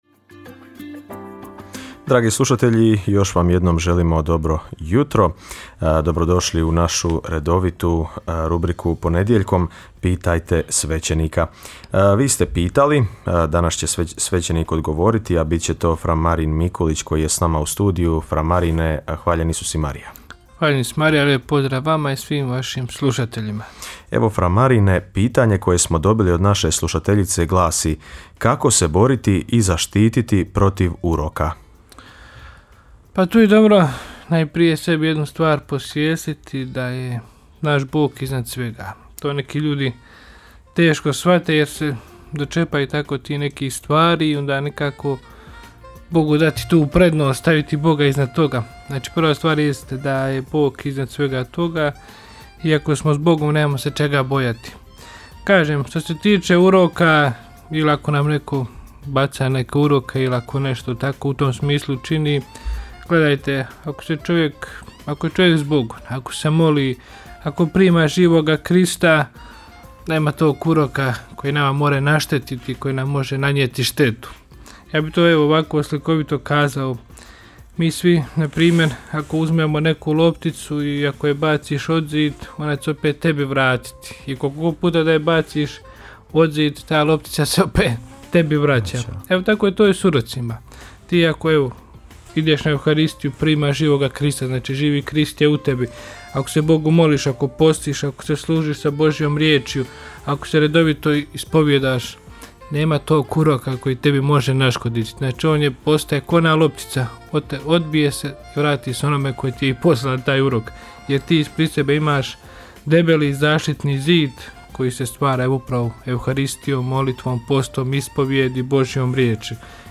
U njoj na pitanja slušatelja odgovaraju svećenici, suradnici Radiopostaje Mir Međugorje.